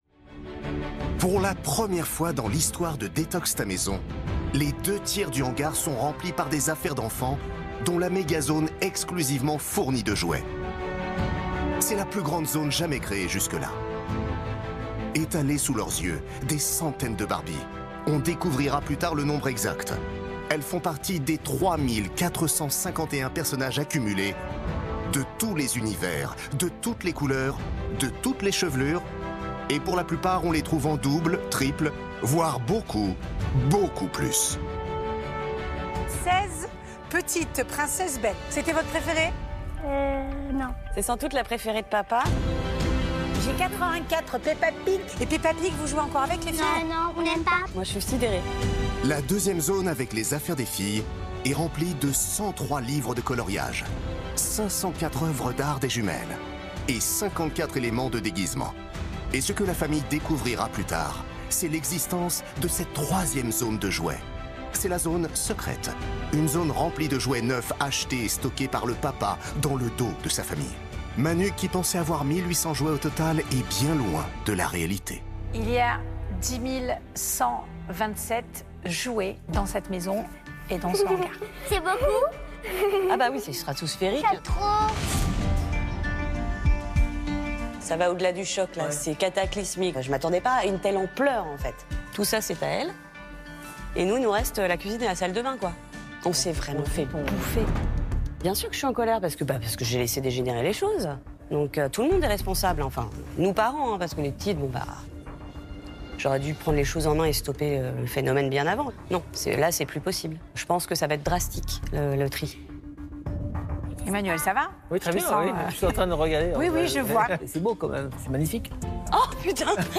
Commentaires voix off d'émission TV pour TFX "Détox ta maison"
Factuel, chaleureux et positif.
Enregistré et mixé chez So Watt.
L’émission est très utile et la voix off accompagne les experts et porte un regard complice et emphatique sur les familles.